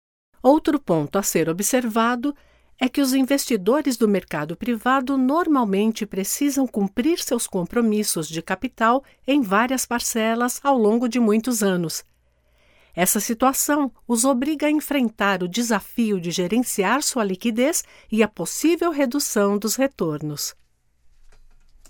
Voces profesionales brasileñas.
locutora Brasil, Brazilian voice over